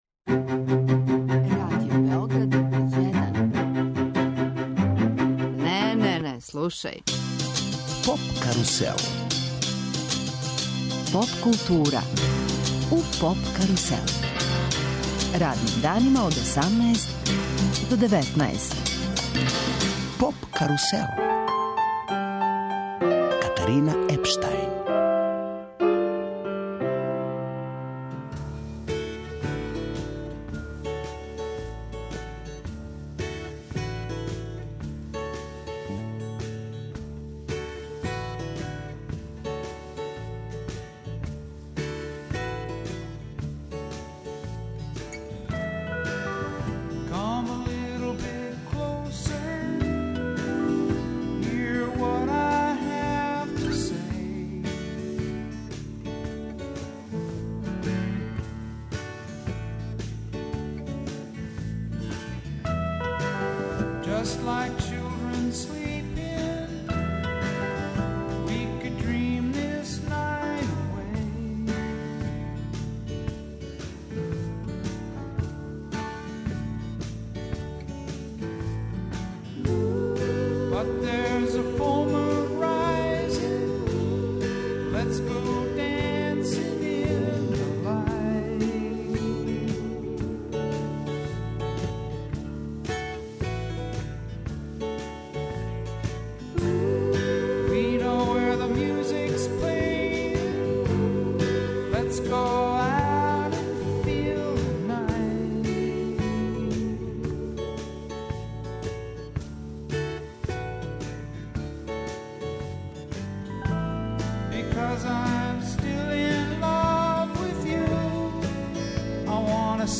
Радио Београд ове године обележава 90 година постојања. То је повод да у емисији разговарамо